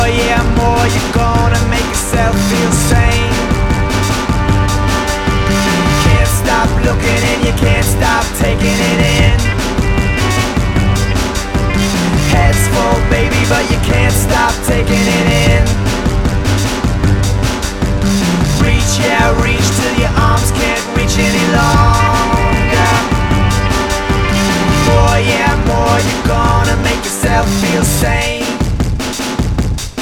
groupe anglais de pop et "trip hop" formé à Londres en 1994
Rock (musique) Musique électronique -- Trip hop